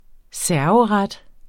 Udtale [ ˈsæɐ̯vəˌʁad ]